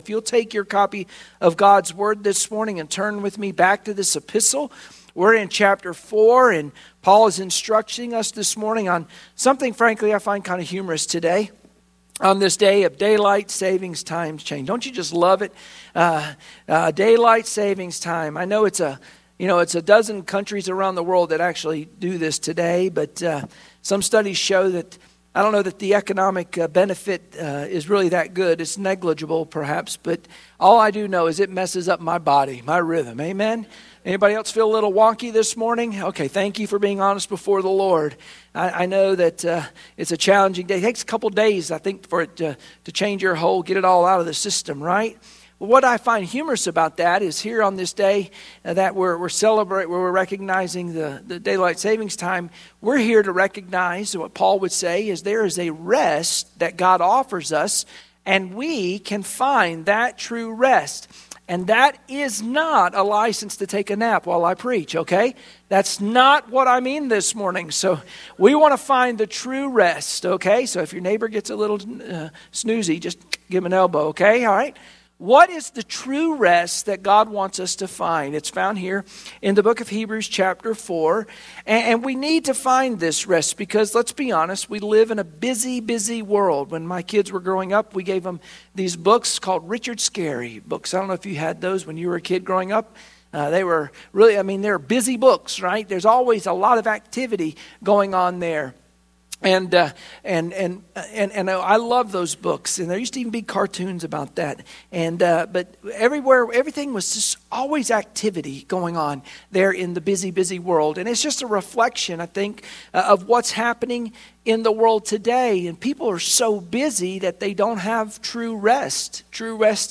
Sunday Morning Worship Passage: Hebrews 4:1-10 Service Type: Sunday Morning Worship Share this